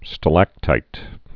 (stə-lăktīt, stălək-)